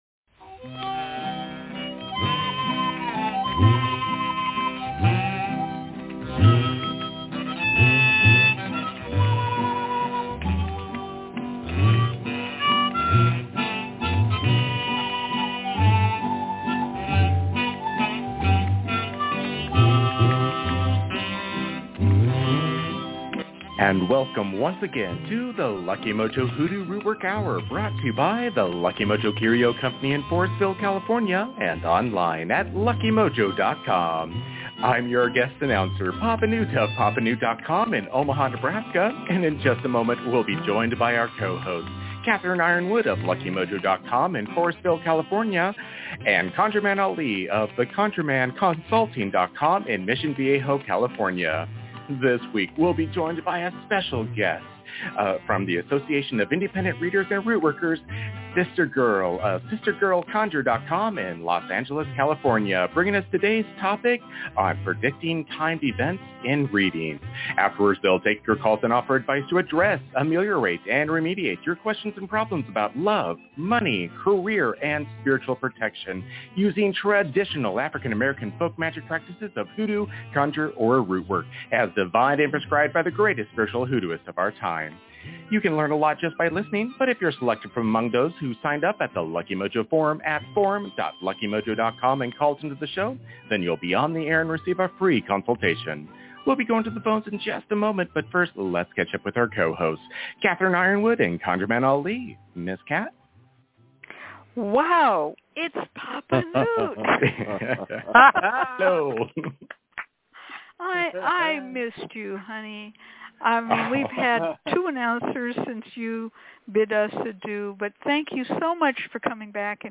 tutorial
free psychic readings, hoodoo spells, and conjure consultations